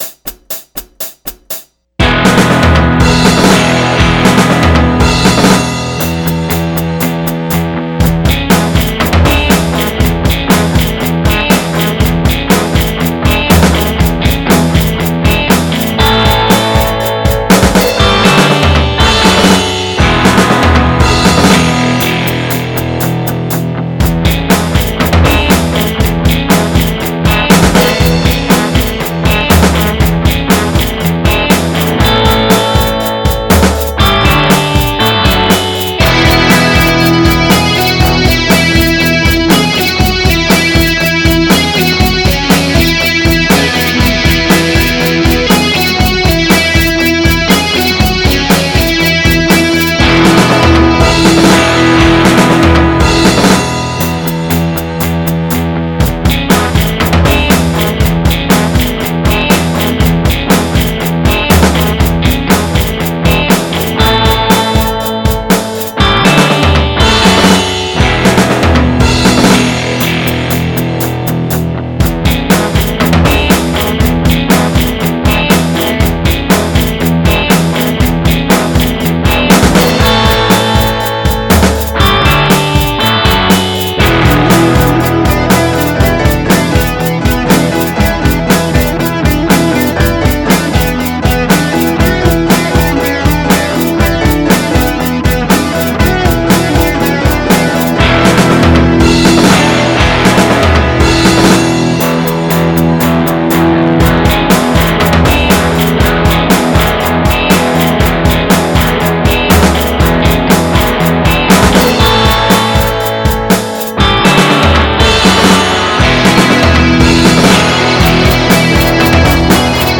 Epiphone Les Paul CustomI know that after a long weekend of partying and relaxing, what you want is some amateurish songwriting and geetar playing to get you psyched up for the work week to come.
No, I cannot do anything about the general quality of the mix, as I am incompetent.